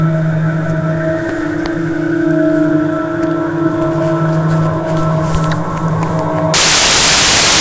Uranus Space Sound Effect Free Download